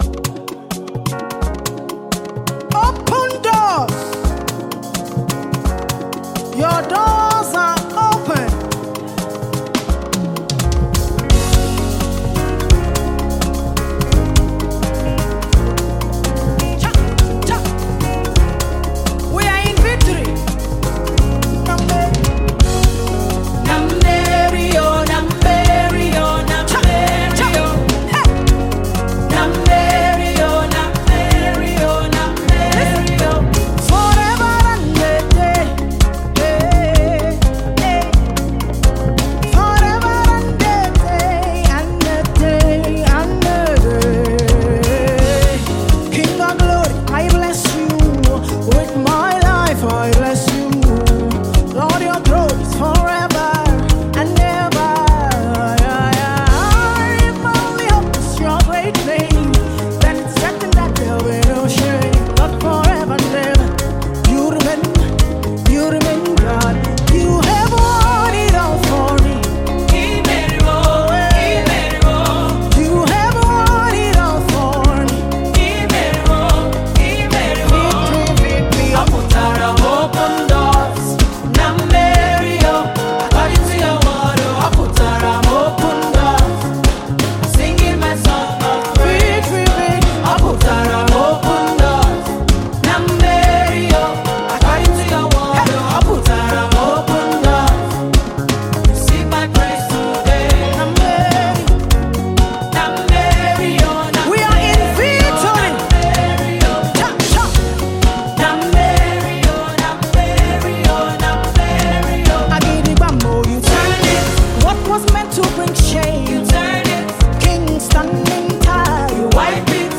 Praise music
Praise Gospel music track